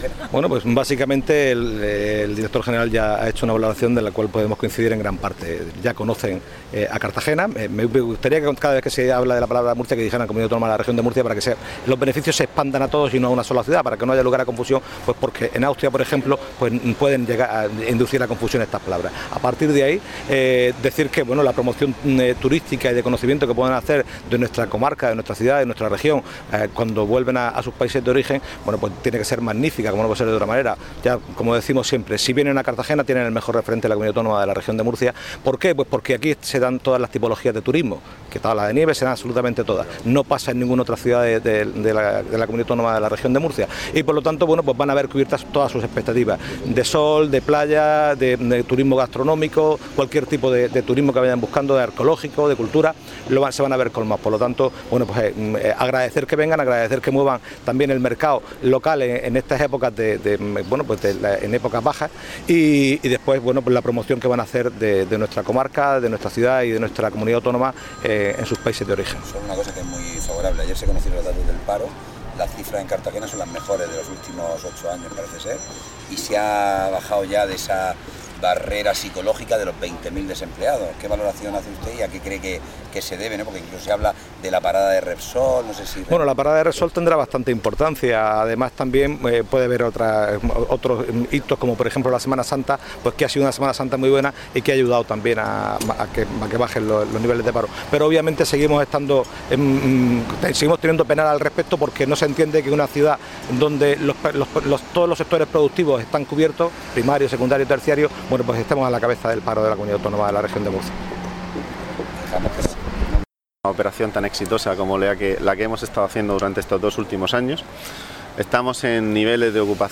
En la primera recepción esta mañana, jueves 5 de mayo, el alcalde, José López, ha dedicado unas palabras de bienvenida a estos turistas para invitarles a conozcan las oportunidades que ofrece la ciudad de Cartagena, donde el Sol es uno de sus principales protagonistas.
Respondiendo a los periodistas en referencia a la bajada de la cifra del paro por debajo de 20 mil personas, el alcalde, José López, ha señalado que la parada de Repsol tiene gran importancia además de otros hitos como la Semana Santa que ha ayudado a que bajen los niveles de paro.